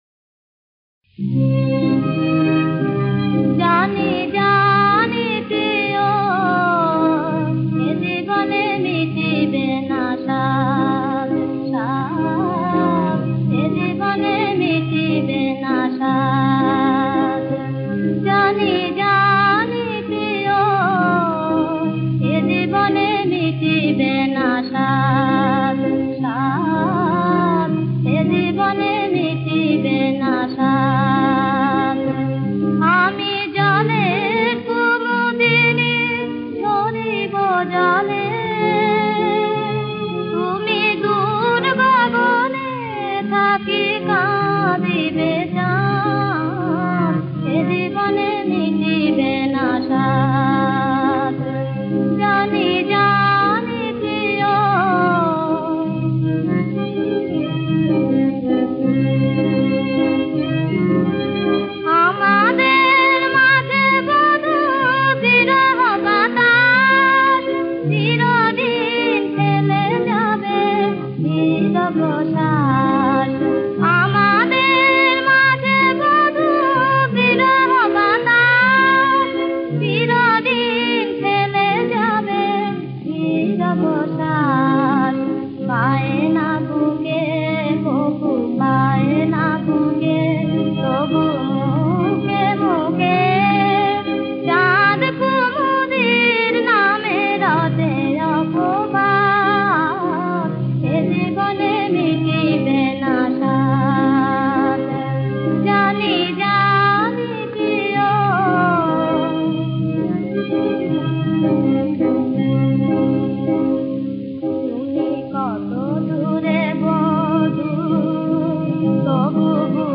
নজরুল সঙ্গীত